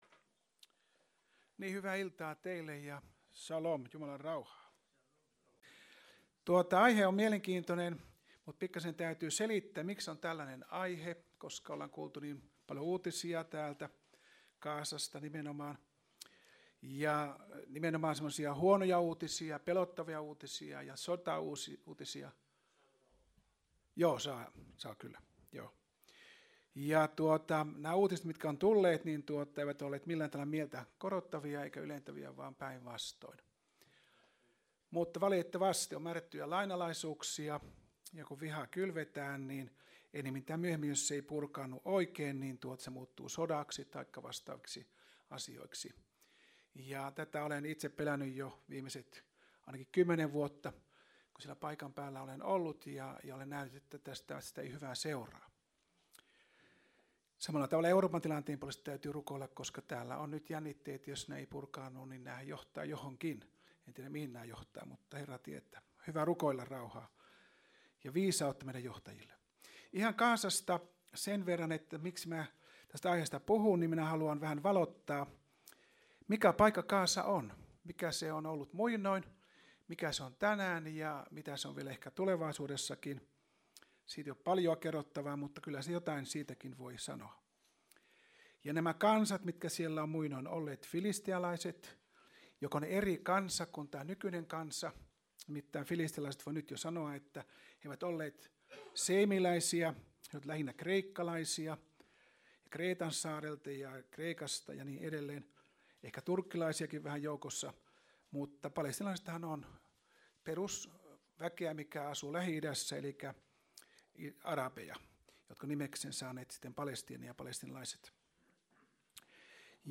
Vantaan Kotikirkko - Kuuntele puheita netissä